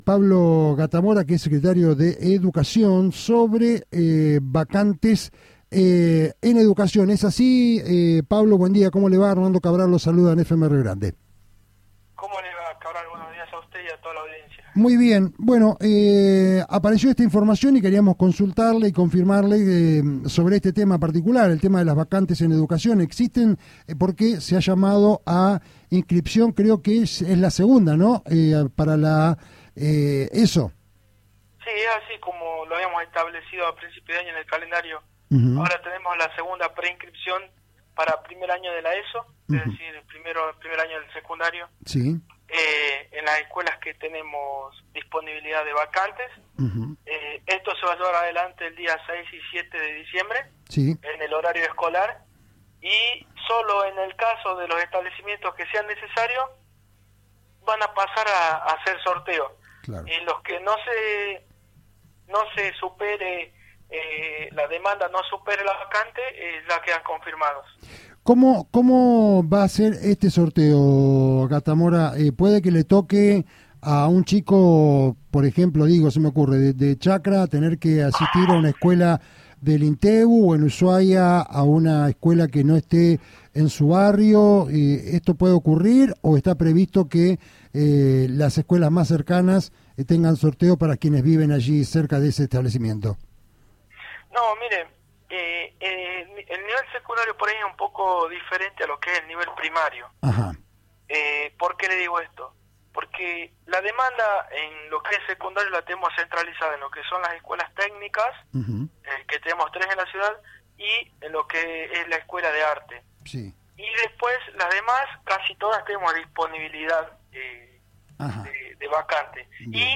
En diálogo con este medio el Secretario de Educación, Pablo Gatamora manifestó que no hay problemas con las vacantes ya que solo un tercio de los 50 mil alumnos de la provincia ingresan a la secundaria. El funcionario sostuvo además que puede haber sorteos pero puede ocurrir en la primaria por la migración. Las clases finalizan el 19 de diciembre y comienzan el 6 de marzo de 2019.